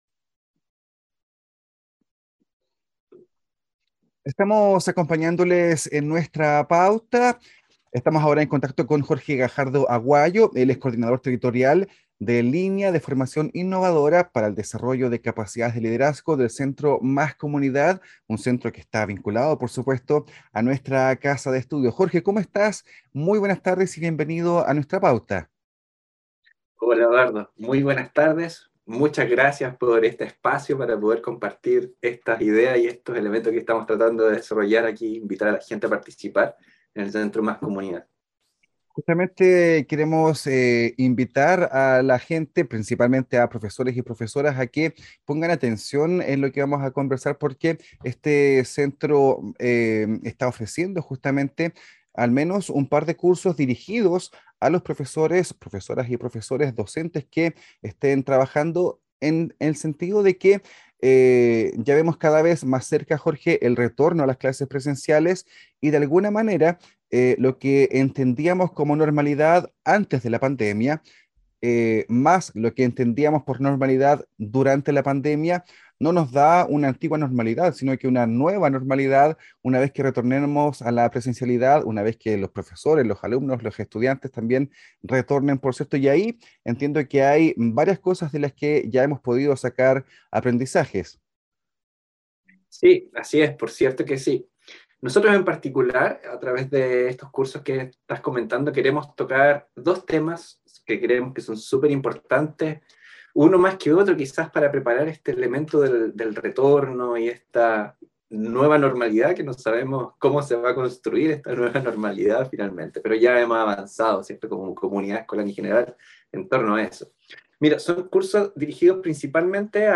Entrevista-Comunidad.mp3